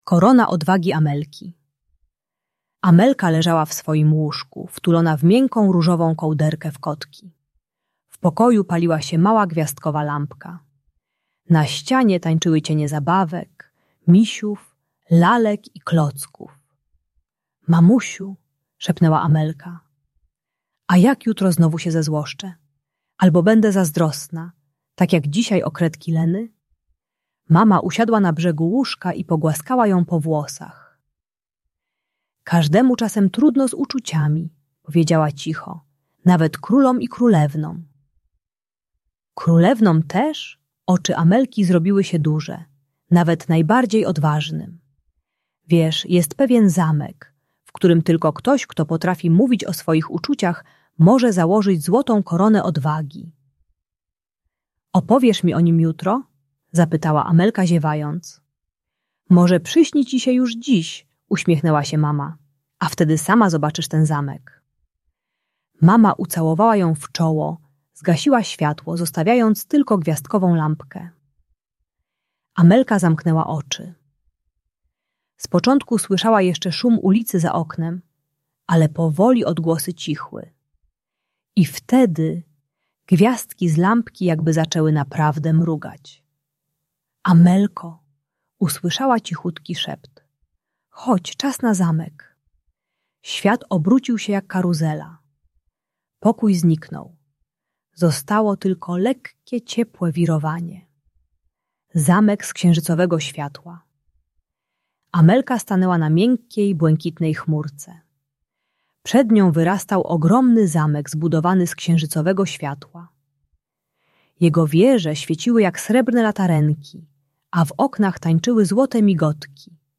Bajka dla dziecka które ma wybuchy złości i zazdrości, idealna dla przedszkolaków 4-6 lat. Audiobajka uczy techniki nazywania emocji na głos zamiast krzyczenia czy popychania innych dzieci. Pomaga maluchowi zrozumieć, że wstyd, zazdrość i złość to sygnały, o których można spokojnie powiedzieć dorosłemu.